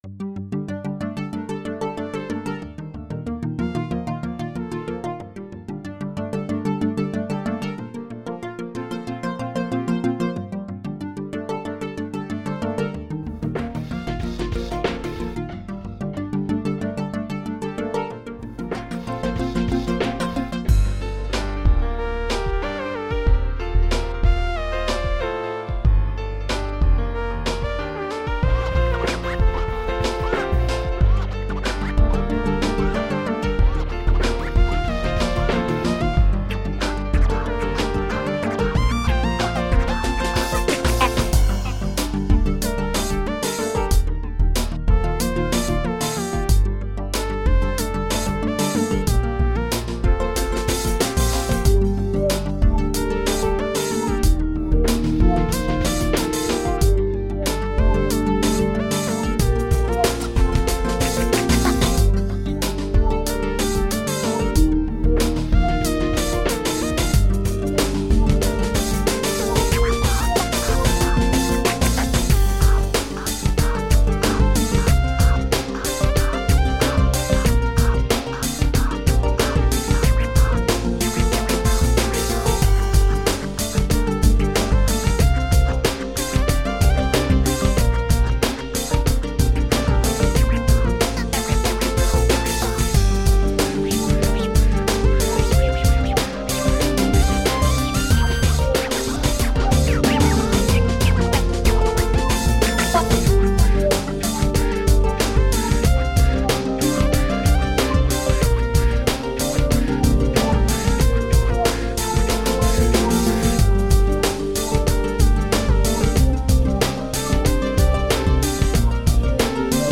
Chill out compilations